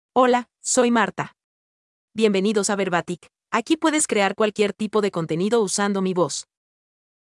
Marta — Female Spanish (Guatemala) AI Voice | TTS, Voice Cloning & Video | Verbatik AI
Marta is a female AI voice for Spanish (Guatemala).
Voice sample
Marta delivers clear pronunciation with authentic Guatemala Spanish intonation, making your content sound professionally produced.